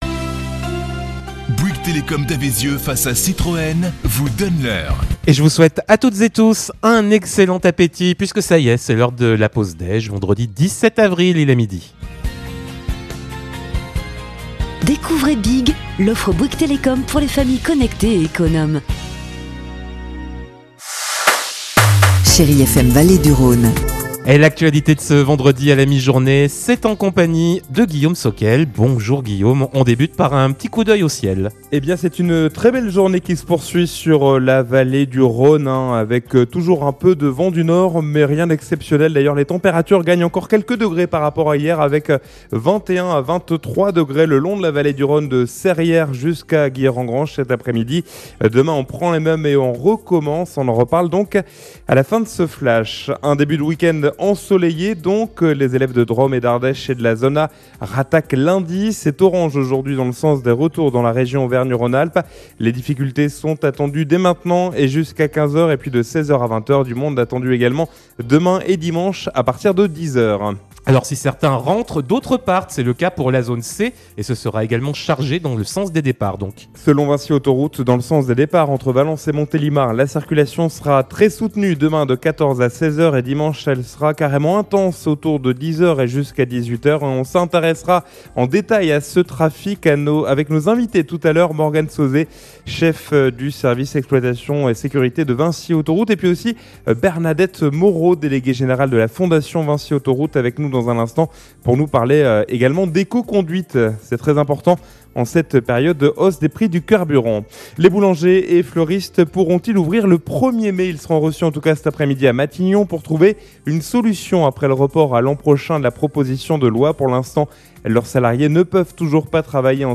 in Journal du Jour - Flash
Vendredi 17 avril : Le journal de 12h